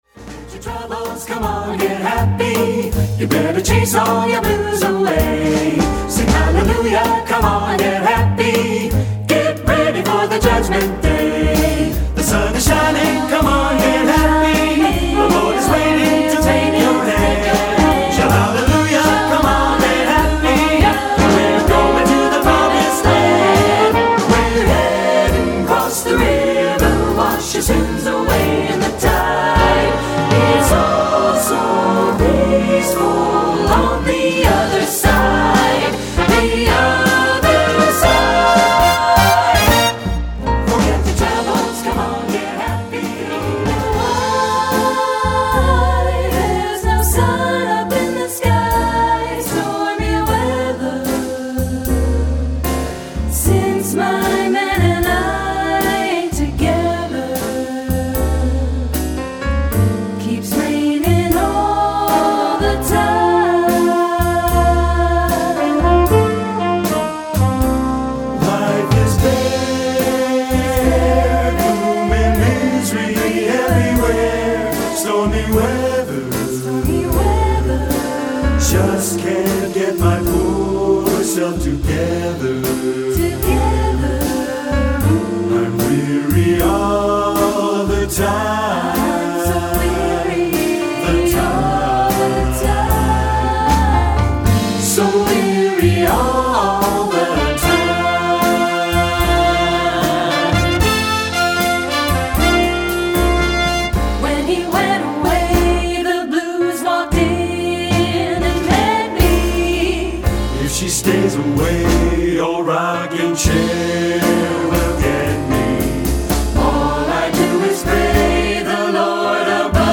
Choral Jazz